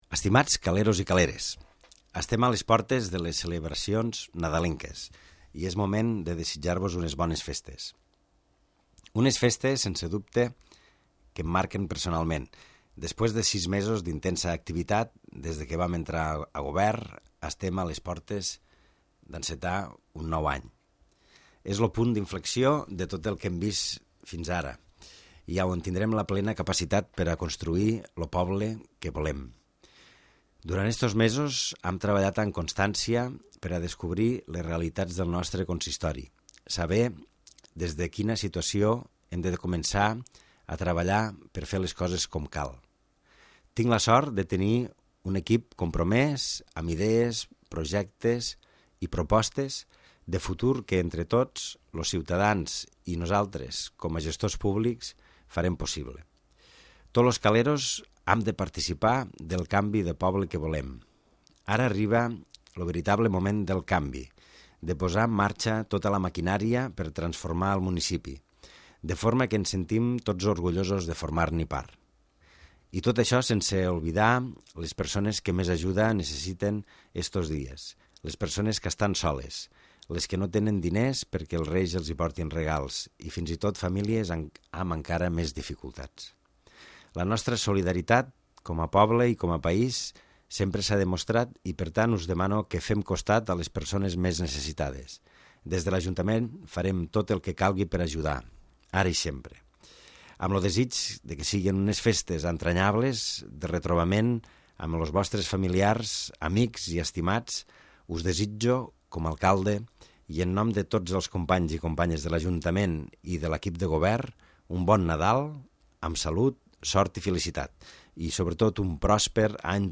Missatge de Nadal de l'Alcalde de l'Ametlla de Mar
L'alcalde de l'Ajuntament de l'Ametlla de Mar, Jordi Gaseni, dirigeix unes paraules als veïns i veïnes del municipi per felicitar aquestes festes nadalenques.